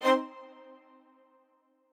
strings5_30.ogg